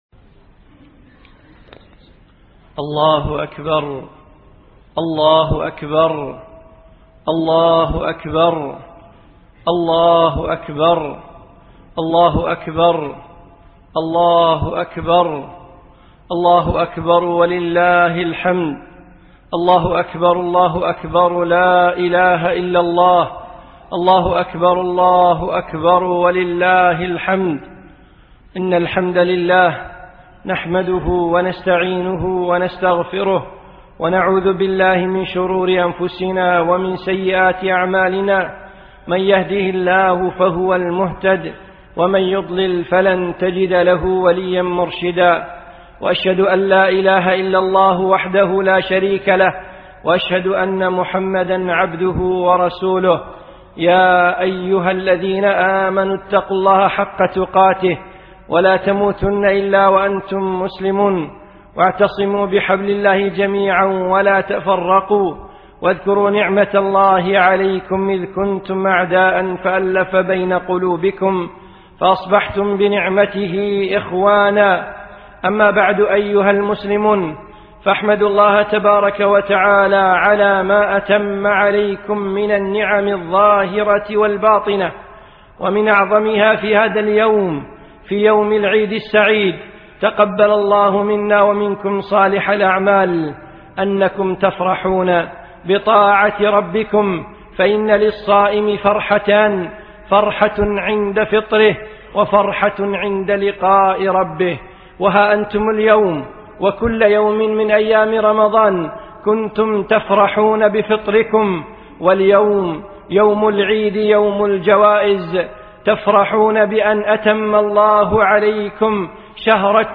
خطبة للشيخ في دولة الإمارات
خطبة العيد عام 1434